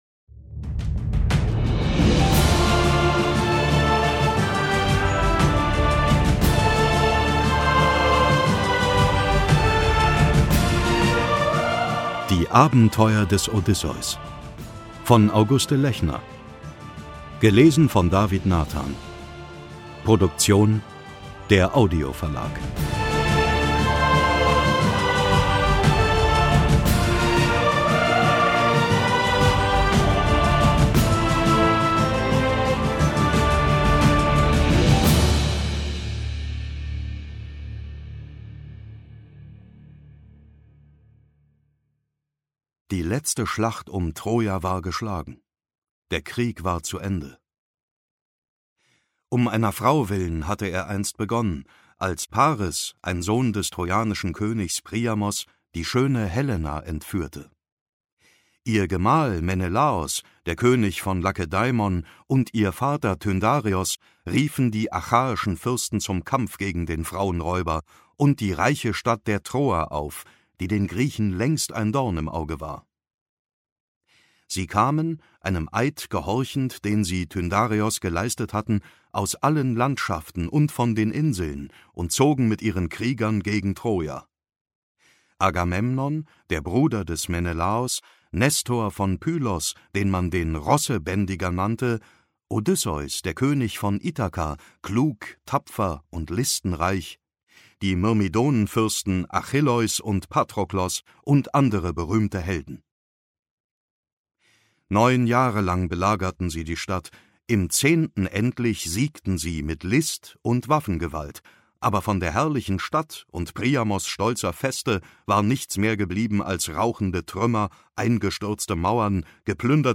Ungekürzte Lesung mit Musik
David Nathan (Sprecher)